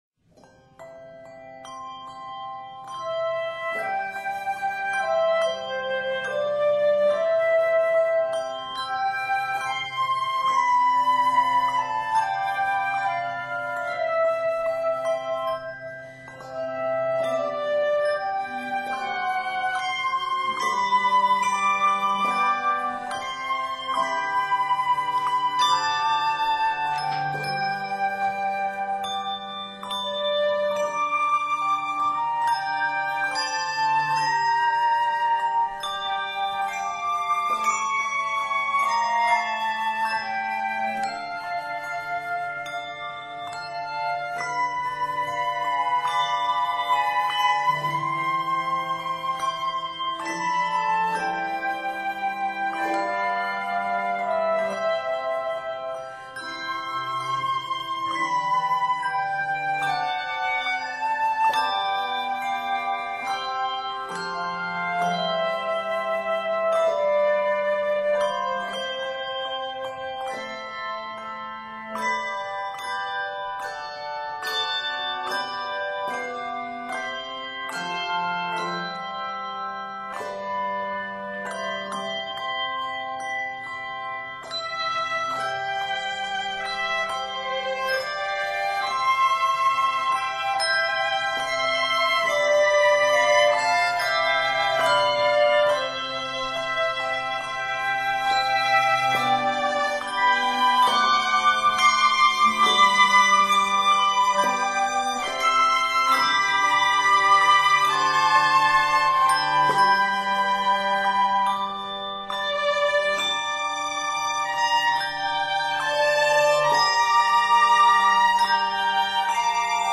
It can be rung on handbells or handchimes alone.